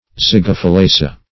Zygophyllaceae \Zy`go*phyl*la"ce*ae\, prop. n.